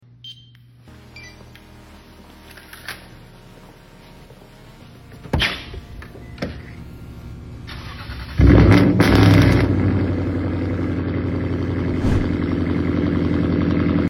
Listen to this Lexus RCF sound effects free download
Listen to this Lexus RCF cold start!